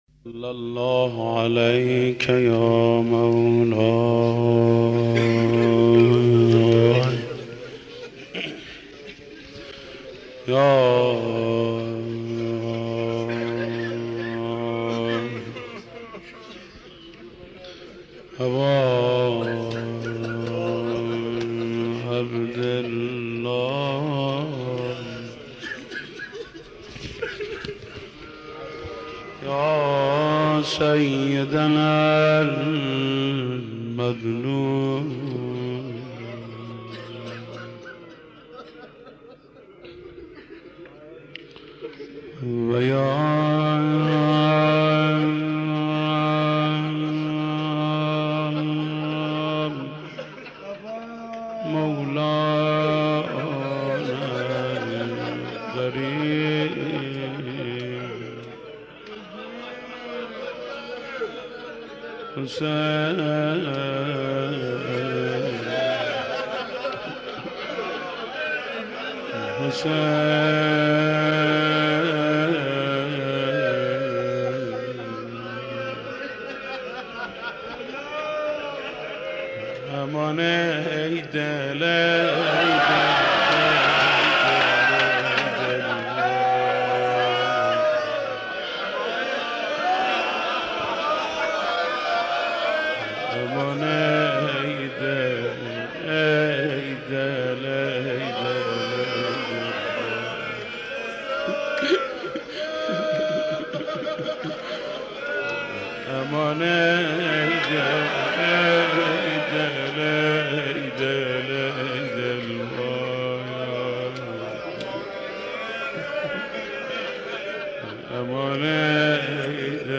مداح
مناسبت : عاشورای حسینی
مداح : محمود کریمی